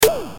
sonic 2 – TV pop